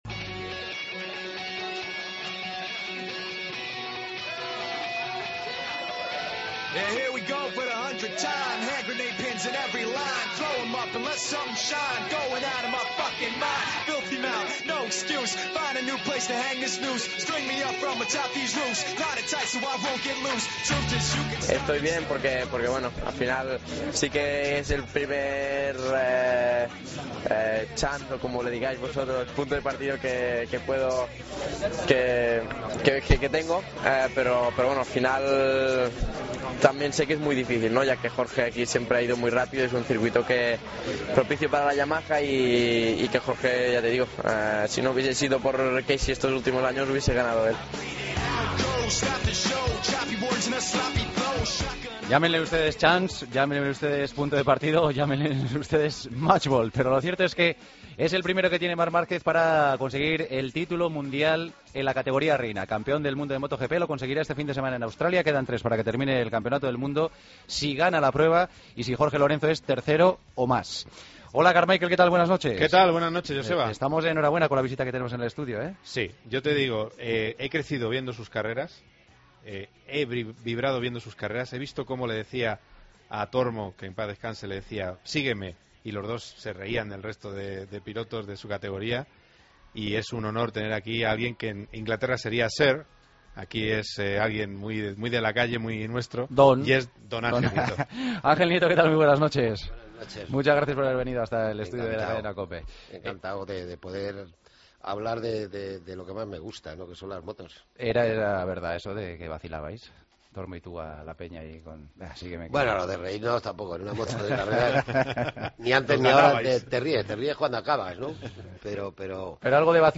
Entrevista a Ángel Nieto, en El Partido de las 12